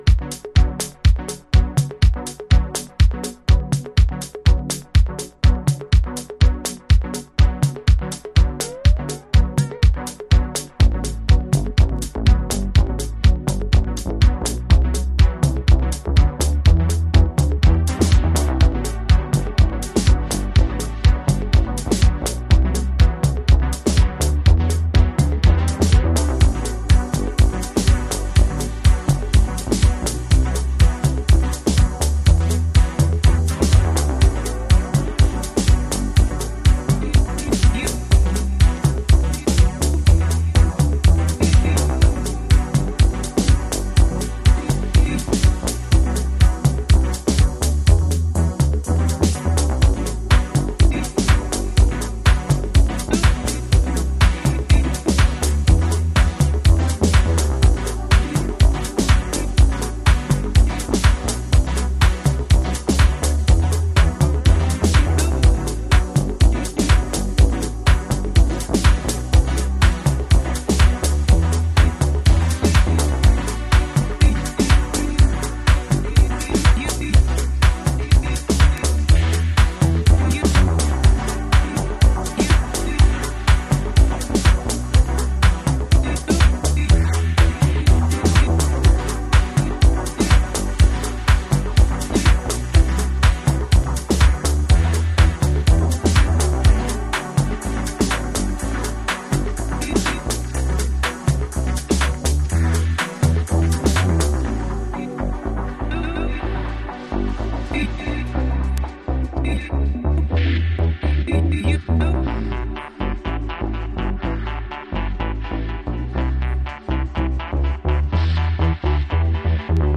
House / Techno
この方の繊細音響が生む、吸着力というか、独創的なグルーヴは中毒性高いです。